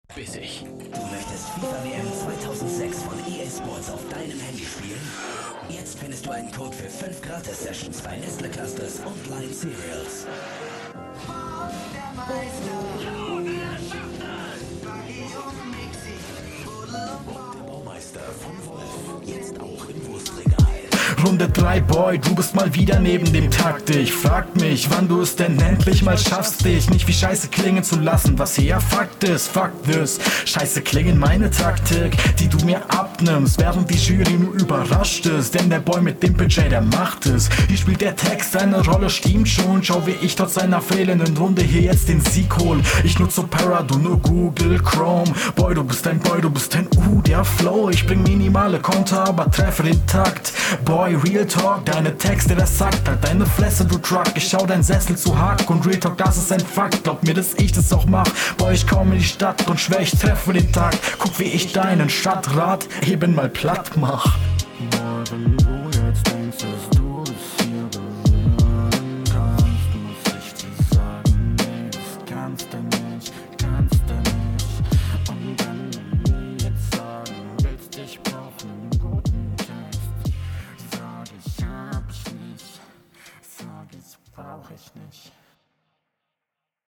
Versteh das Intro nicht, aber hey die Runde klingt ja sogar stabil.